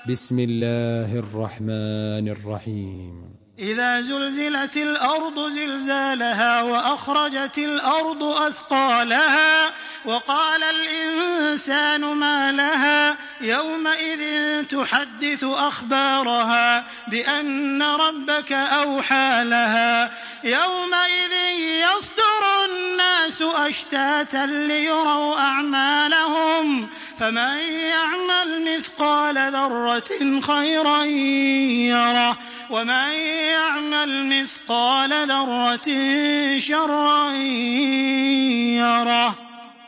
Surah Az-Zalzalah MP3 by Makkah Taraweeh 1426 in Hafs An Asim narration.
Murattal